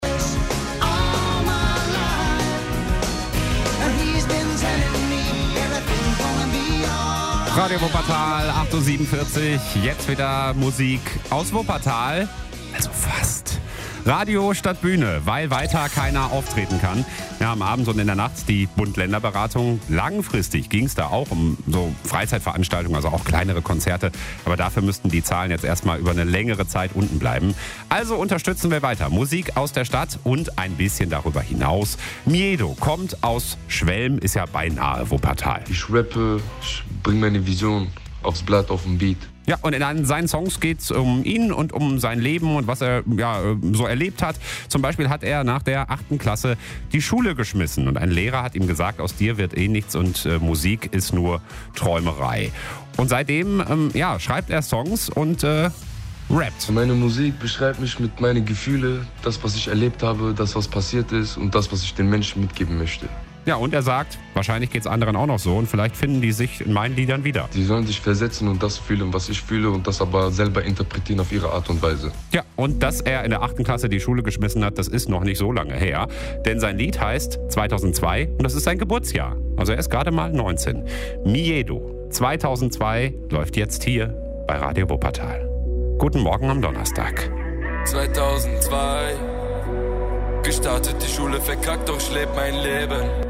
Rap.